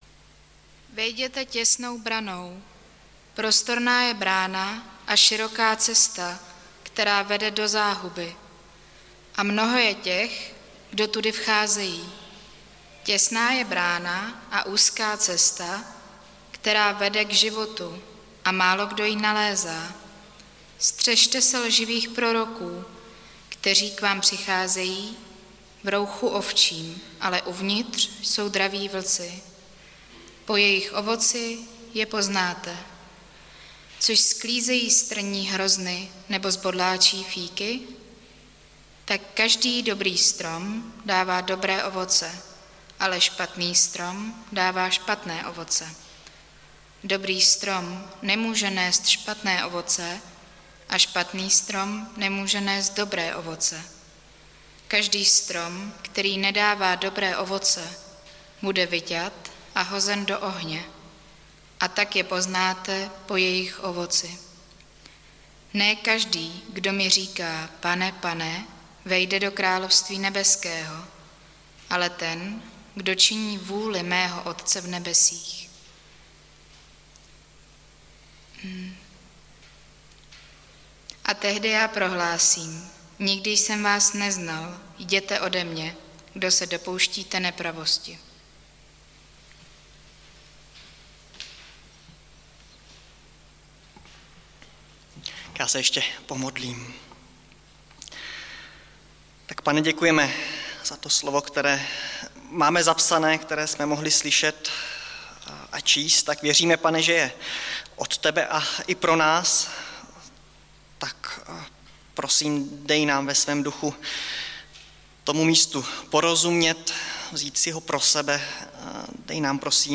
Nedělní kázání – 21.2.2022 Úzká cesta